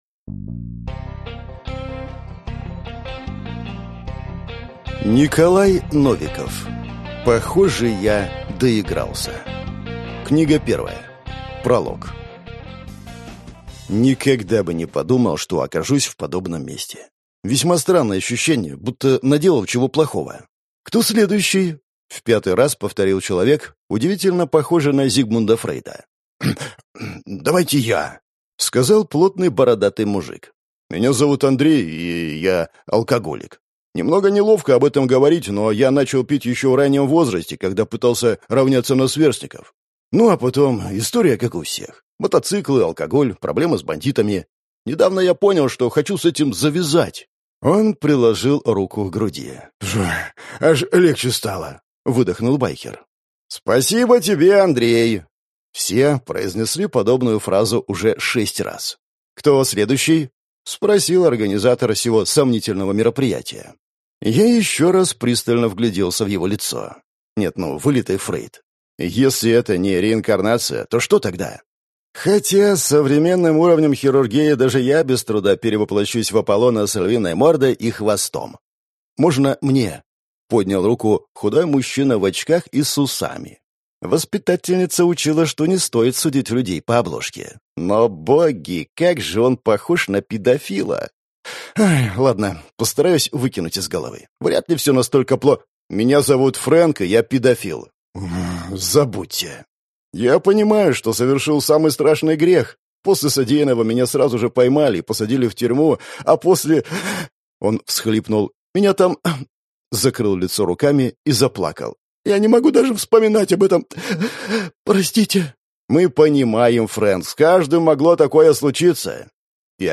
Аудиокнига Похоже, я доигрался. Книга 1 | Библиотека аудиокниг